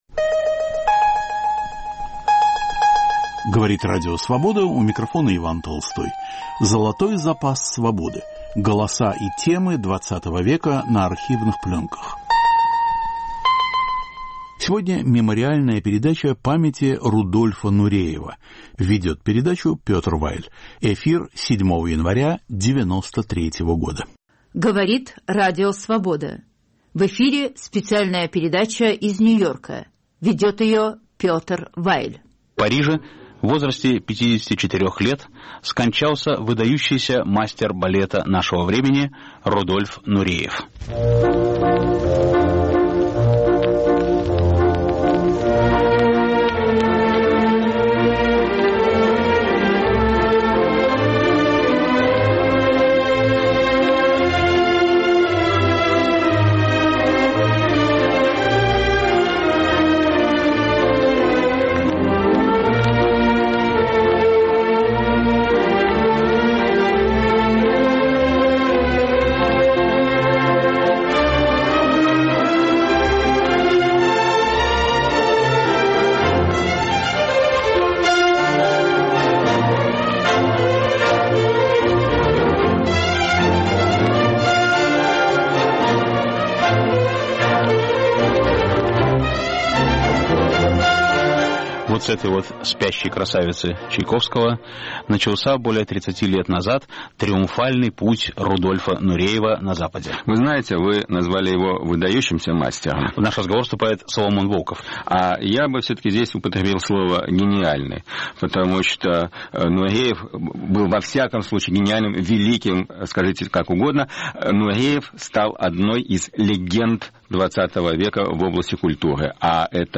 Вспоминая легендарного танцовщика XX века. Передача из Нью-Йорка. Ведущий Петр Вайль, участвует Соломон Волков.
Беседа с Владимиром Маканиным.